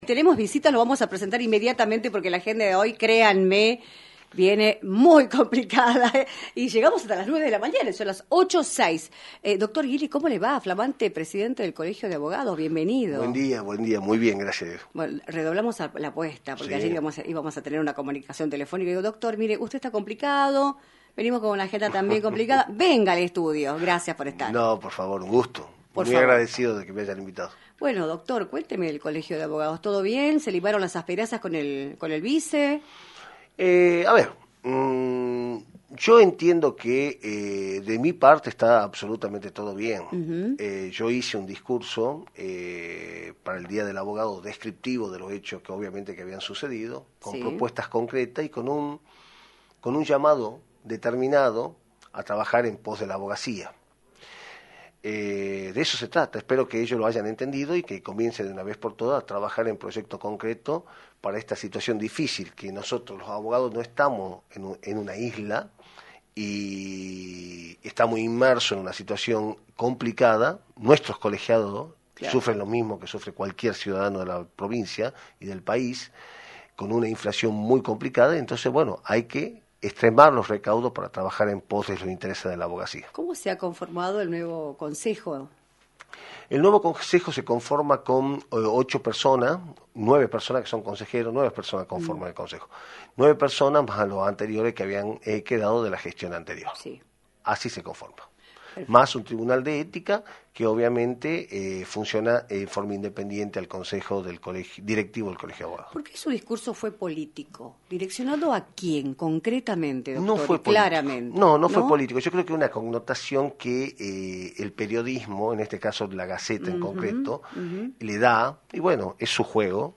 visitó los estudios de «Libertad de Expresión» por la 106.9, para analizar la situación judicial y política de la provincia en relación a sus honorarios y a la calidad de los servicios.
entrevista